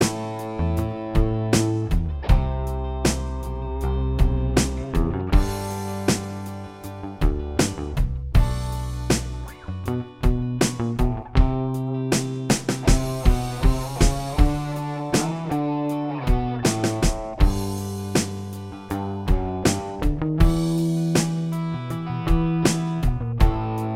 Minus Acoustic Guitar Pop (1980s) 3:21 Buy £1.50